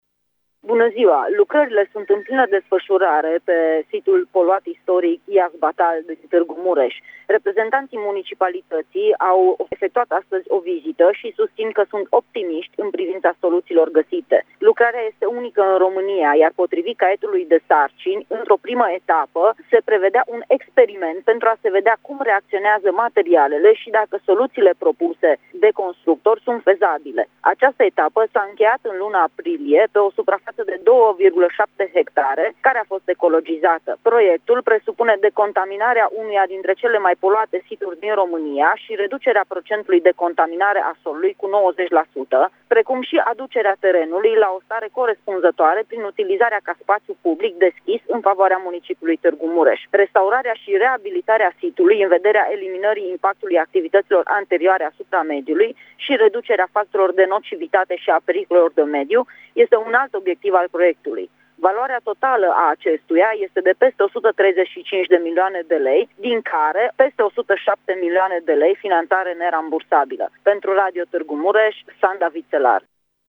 se află la fața locului și ne spune mai multe: